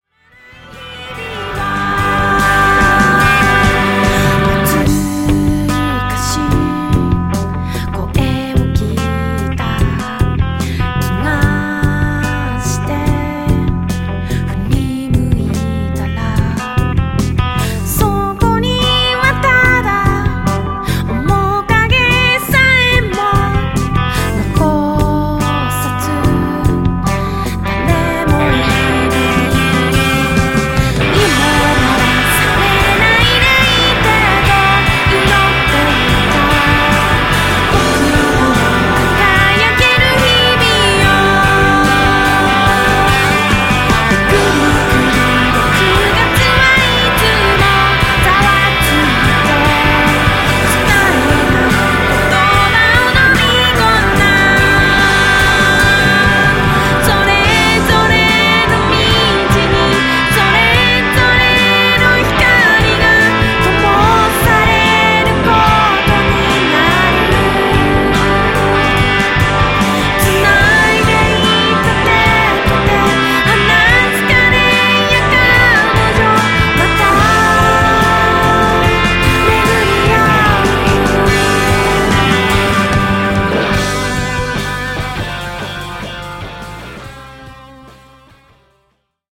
せっかくのヴォーカルなので、もっとしっかり上げ下げして、歌が聴こえにくい部分をなくすとさらに良いでしょう。
（特に1曲目）コーラスアレンジも綺麗。